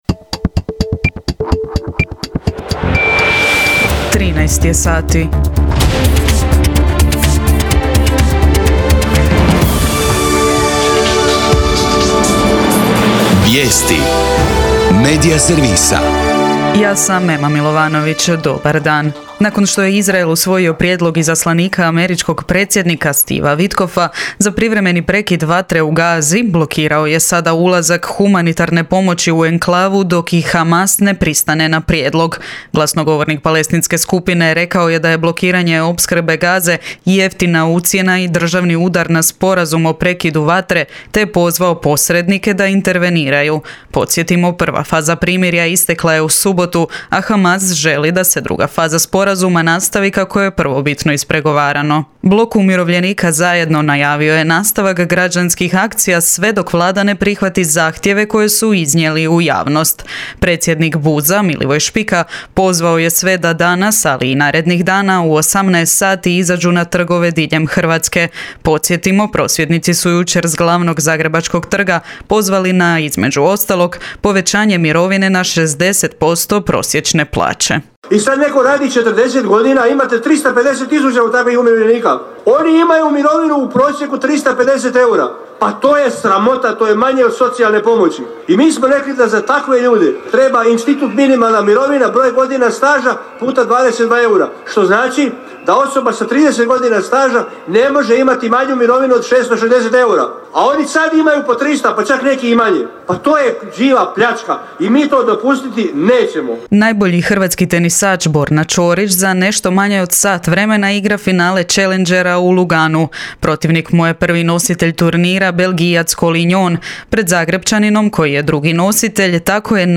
VIJESTI U 13